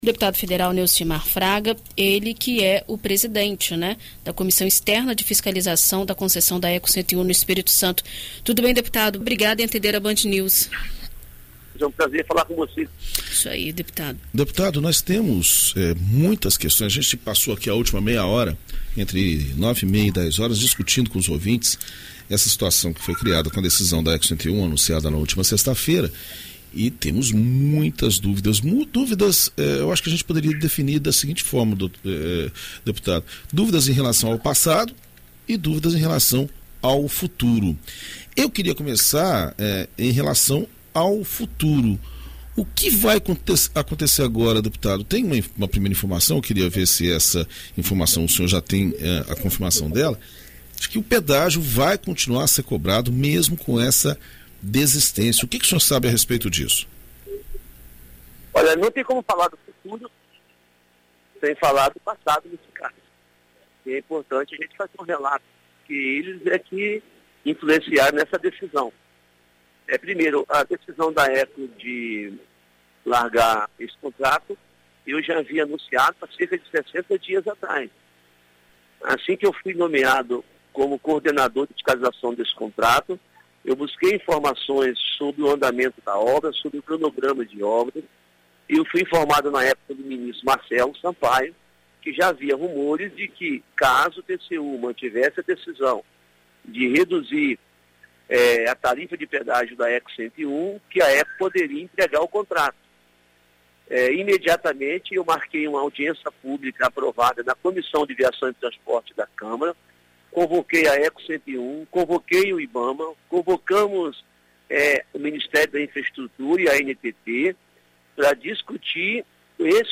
Em entrevista à BandNews FM Espírito Santo nesta segunda-feira (18), o presidente da Comissão Externa de Fiscalização da Concessão da BR-101, deputado federal Neucimar Fraga, conversa sobre o acordo de devolução e as tratativas cumpridas até o momento.